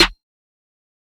snare 4.wav